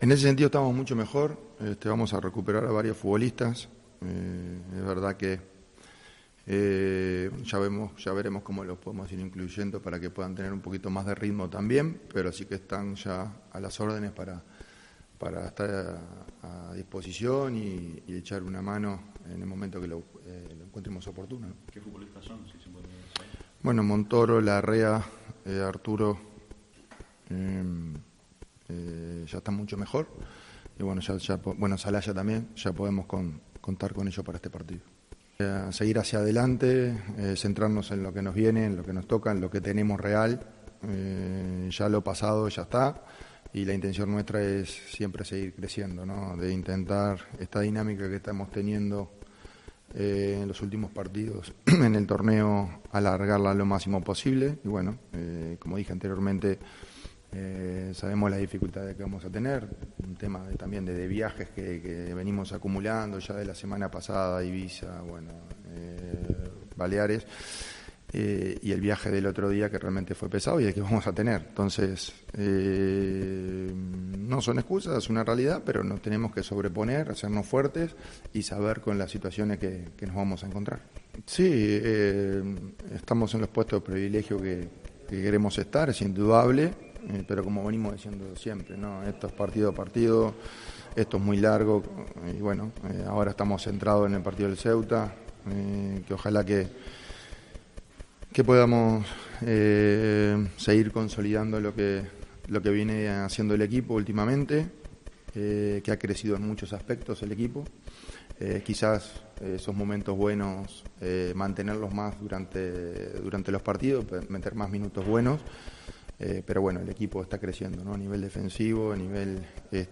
Gustavo Munúa atendió a los medios de comunicación en la rueda de prensa previa a la jornada 11, en la que el Real Murcia se enfrentará al Ceuta este domingo 5 de noviembre a las 16 horas en el estadio Municipal Alfonso Murube.